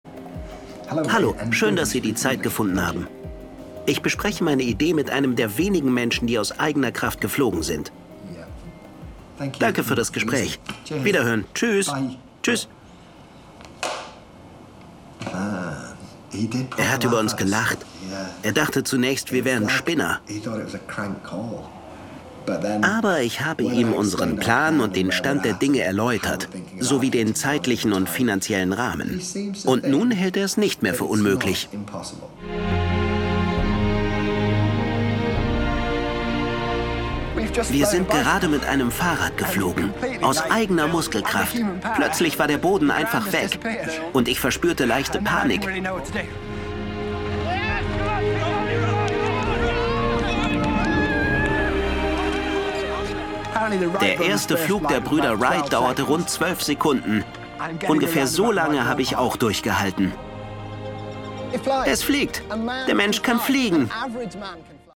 markant, sehr variabel
Jung (18-30)
Doku